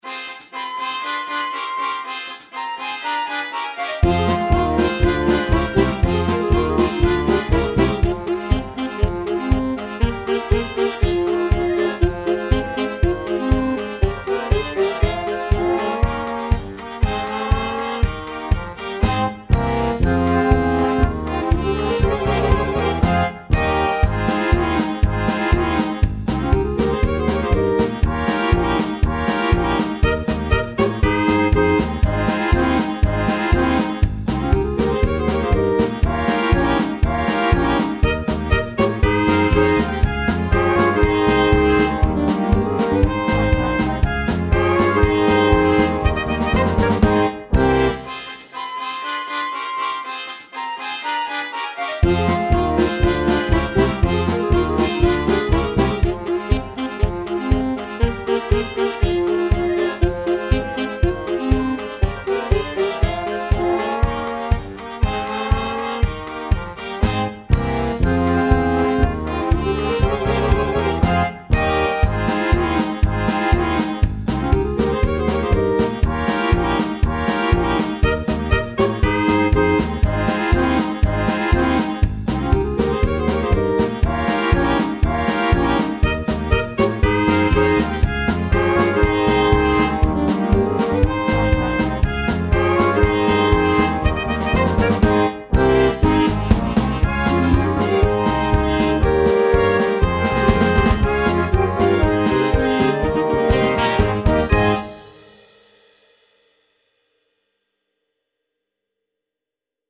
Dychová hudba Značky: Spev , Swing